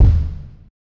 French! Kick.wav